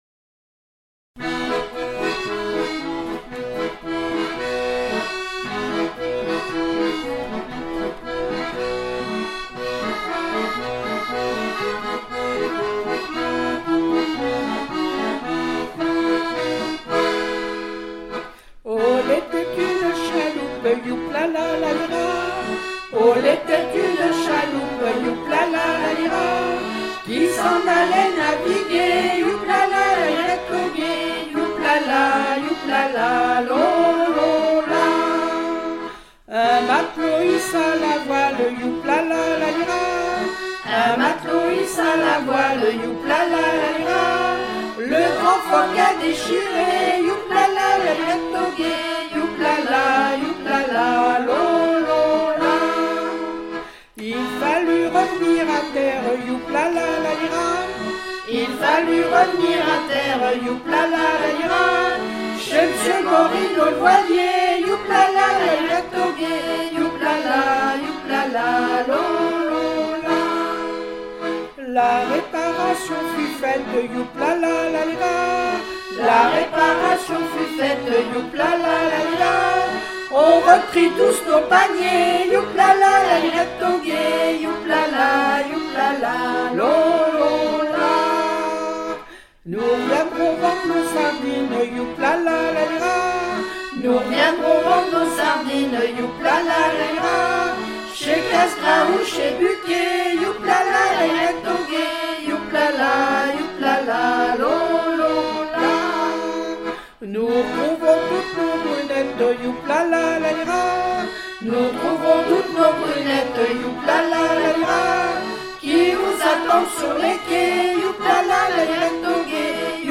O l'était une chaloupe par le groupe filles de Bise-Dur
Pièce musicale inédite